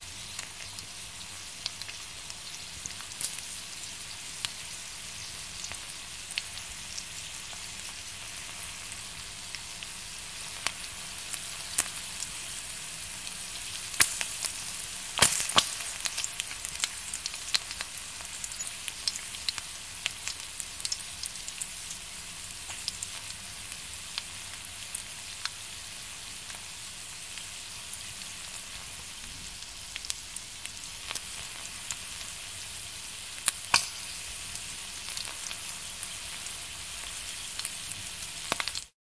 在锅上烹调食物
描述：在锅上烹调食物。
Tag: 厨房 嘶嘶声 煎锅 培根 pan 烹煮 食物 油炸 烹饪